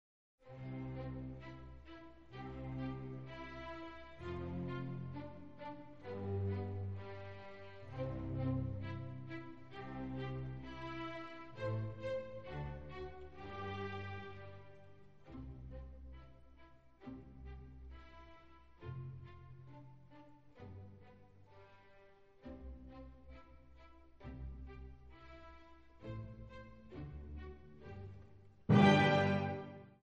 Haydn - Symphony 94 "Surprise"